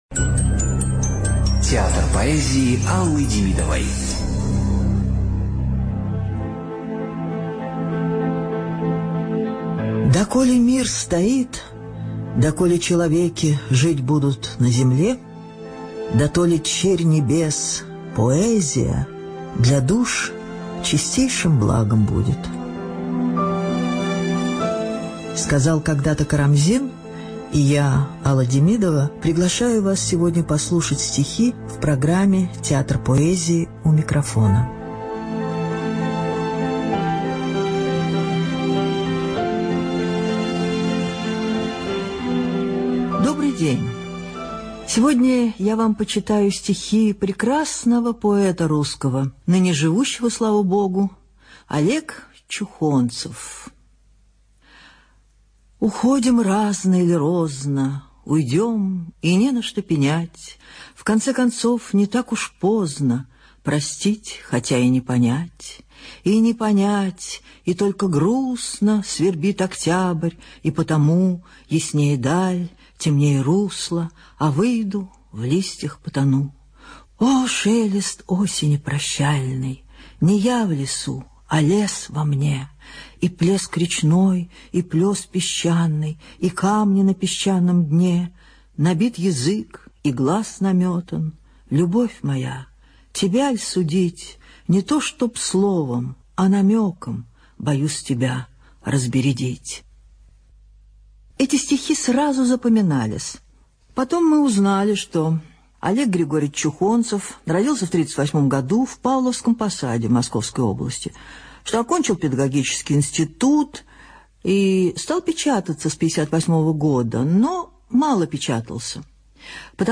ЧитаетДемидова А.
ЖанрПоэзия